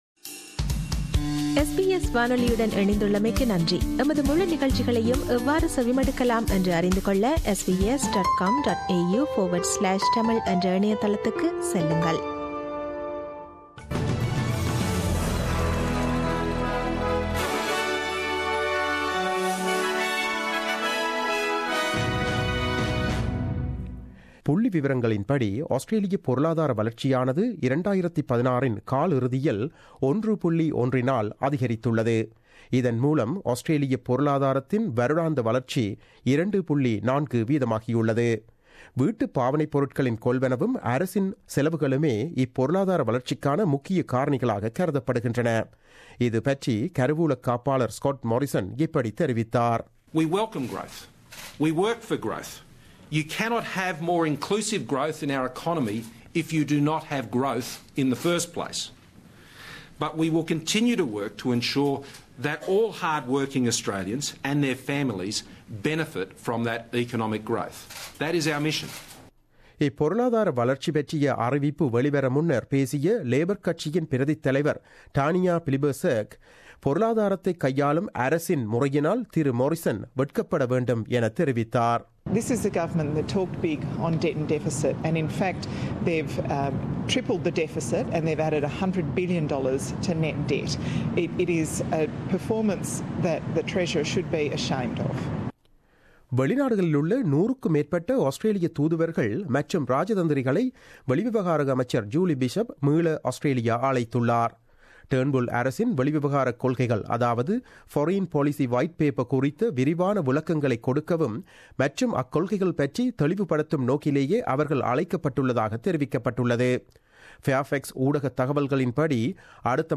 The news bulletin aired on 01 March 2017 at 8pm.